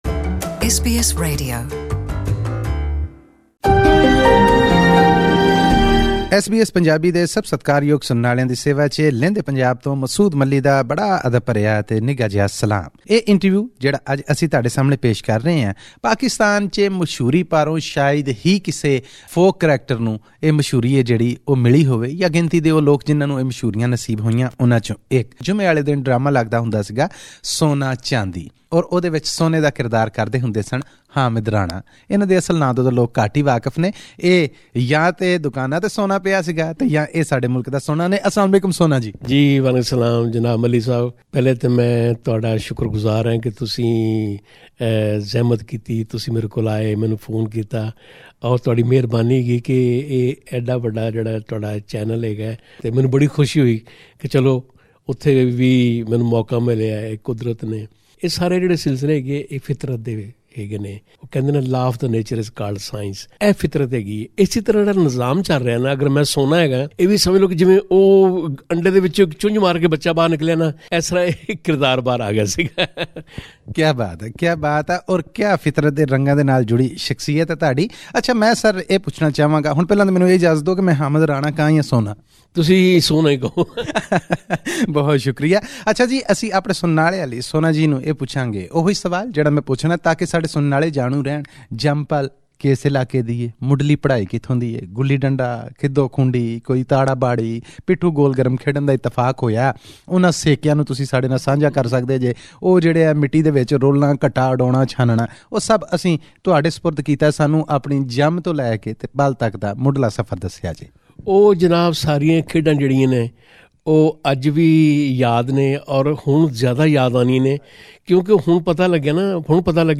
In our fortnightly interview with the who's who from Pakistan's Punjab province, this week, we meet an actor whose character from a popular TV serial, became a household name for Punjabis across the divide.